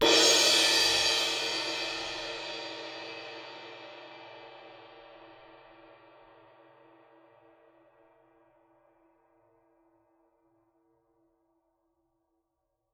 susCymb1-hit_fff_rr1.wav